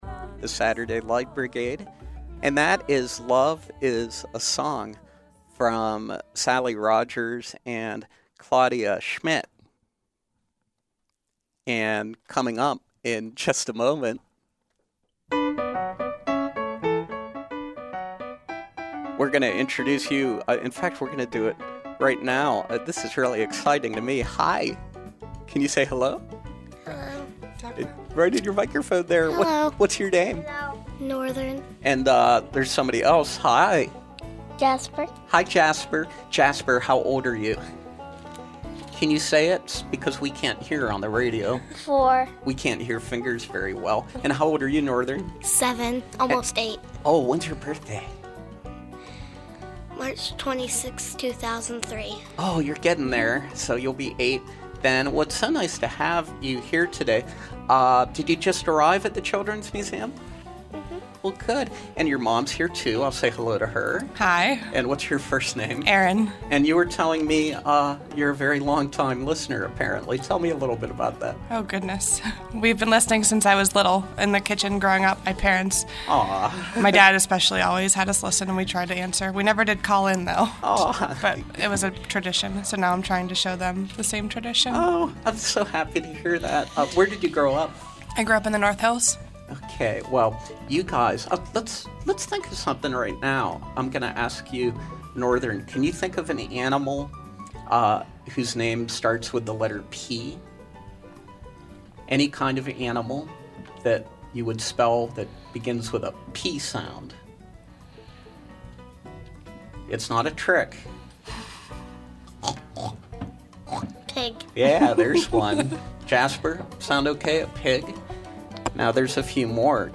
Jasper and Northern join us live on the air.